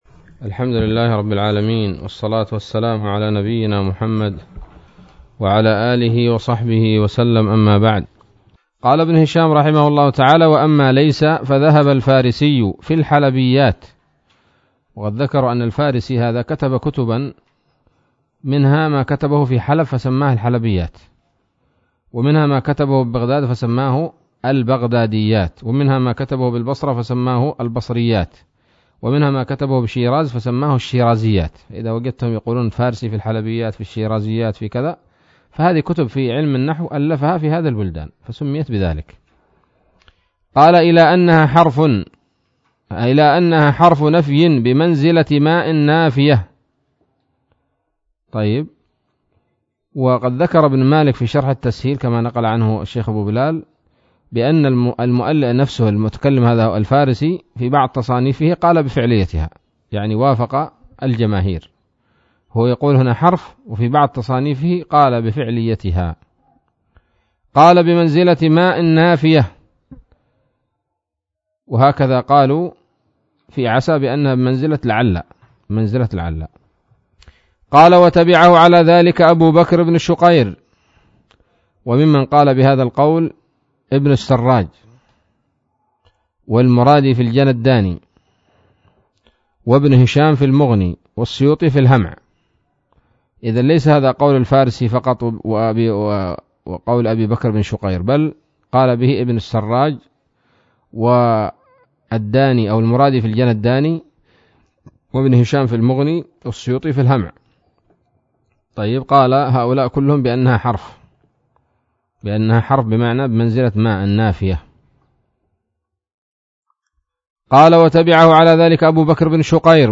الدرس العاشر من شرح قطر الندى وبل الصدى